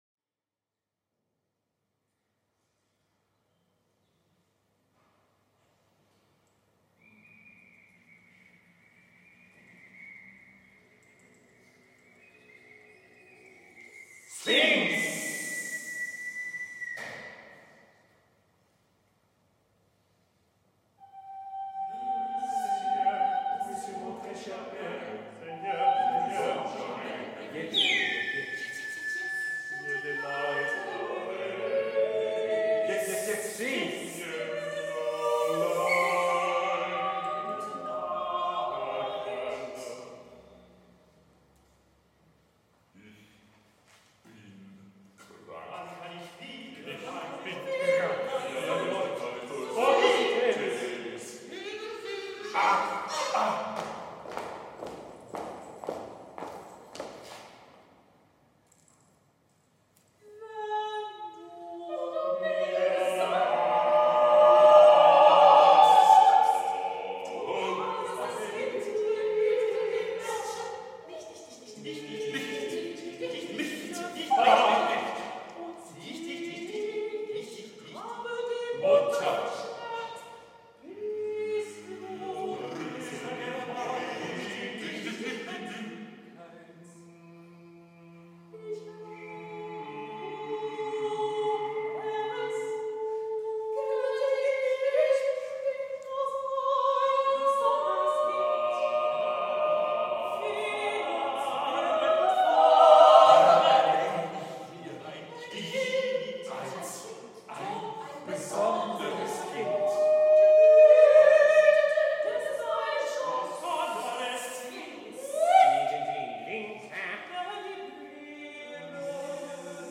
(2 Sopr.,1 Mezzo, 1 Tenor, 1 Baritone, 1 Bass)